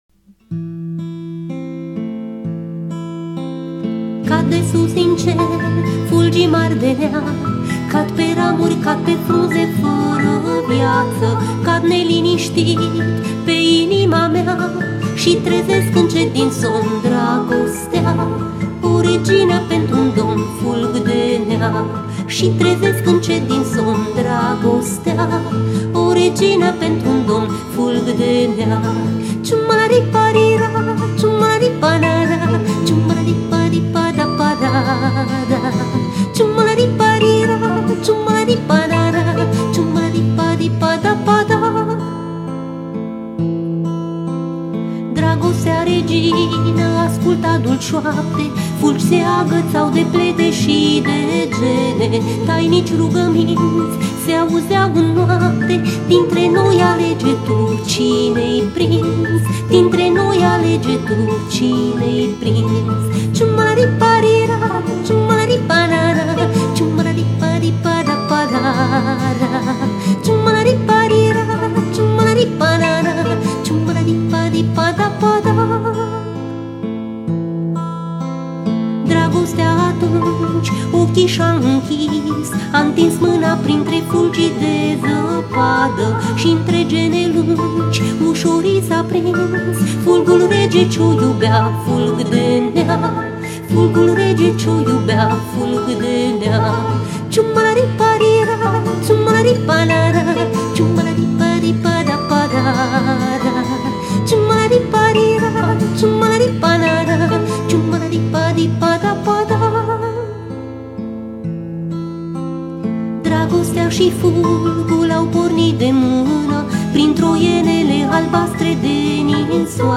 musique, paroles, voix et guitare